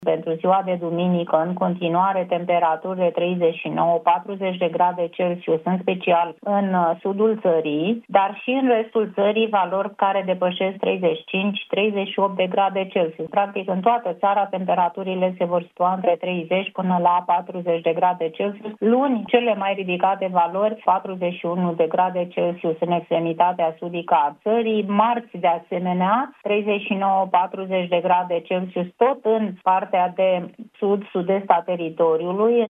Directorul ANM, Elena Mateescu: „Luni, cele mai ridicate valori, 41 de grade Celsius în extremitatea sudică a țării”